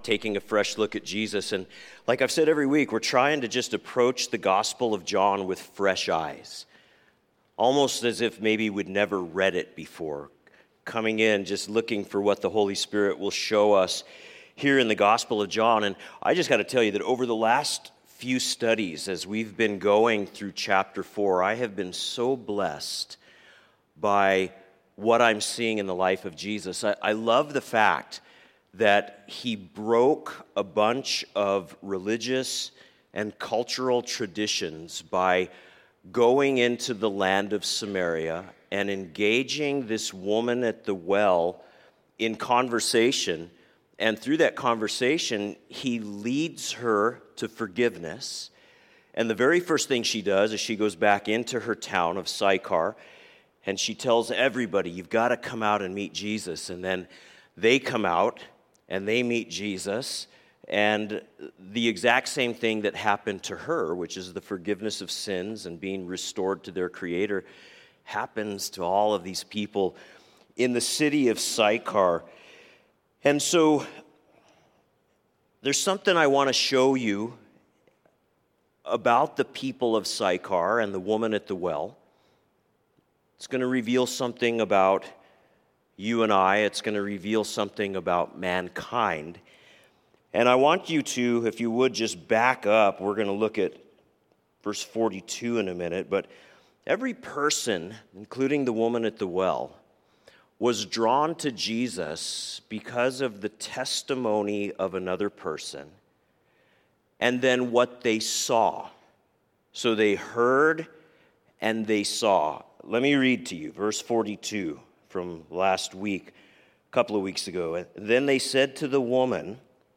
A message from the series "Sunday Morning."